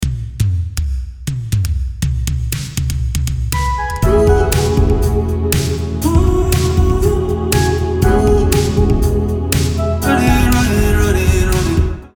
曲中になっているパッド音とリードシンセが「Equator2」の音だけで構成されています。
パッド2つとリードシンセ１つの構成ですが、全体の雰囲気作りを難なくしてくれるのでとても使いやすい印象を受けました。